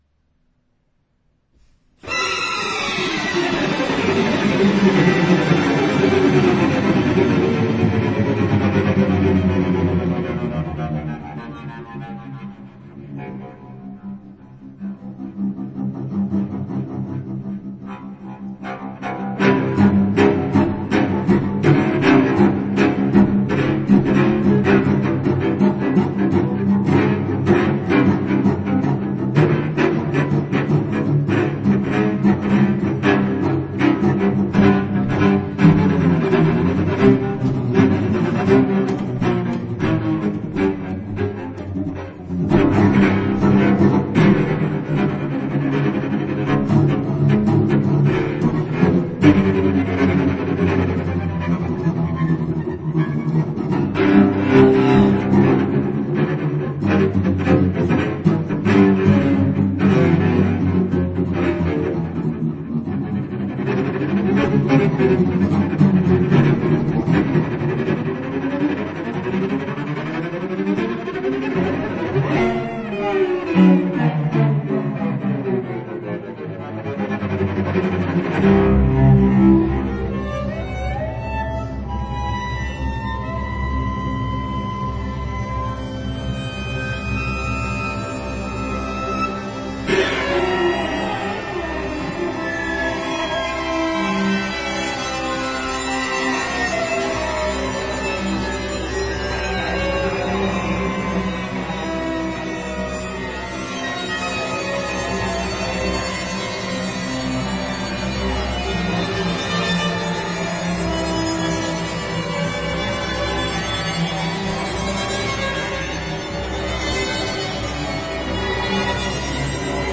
這張現代大提琴作品，就以音樂家為標題，
所以在這張現場錄音的專輯裡，
聽感很強烈，但卻也掏起你心中的洶湧。
這些作品，試圖脫離大提琴的傳統表現方式與音色。